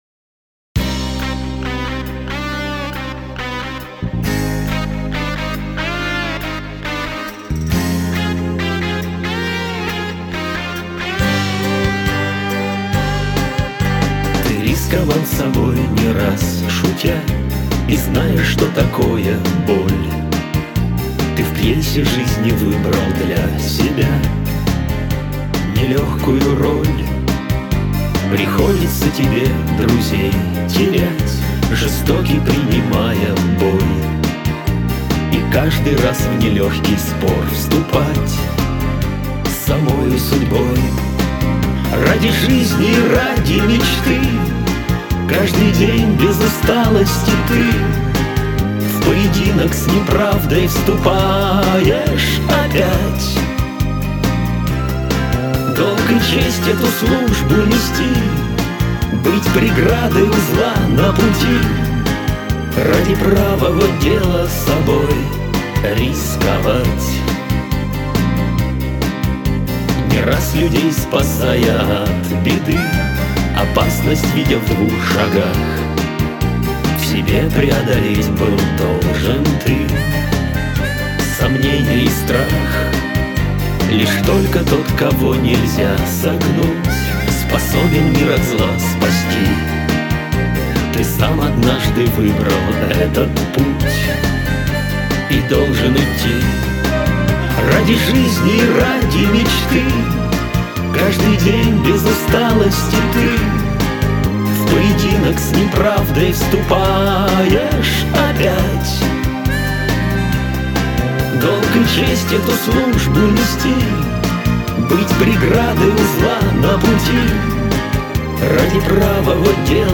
Жанр: Pop
Стиль: Europop